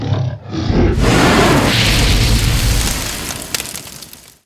fireball.wav